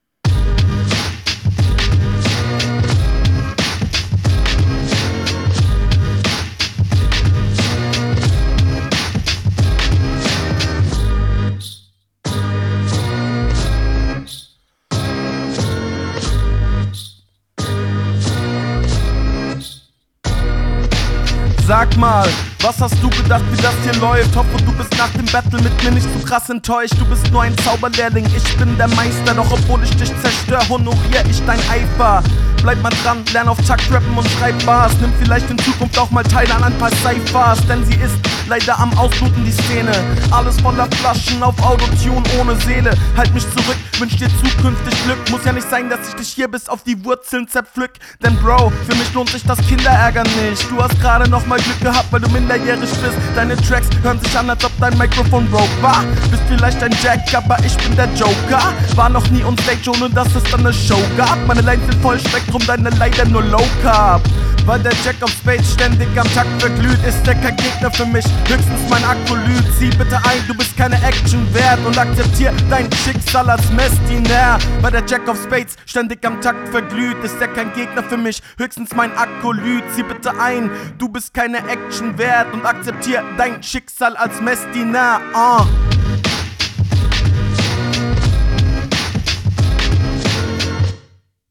Fand die Beatcuts nicht so cool.